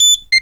pgs/Assets/Audio/Alarms_Beeps_Siren/beep_02.wav
beep_02.wav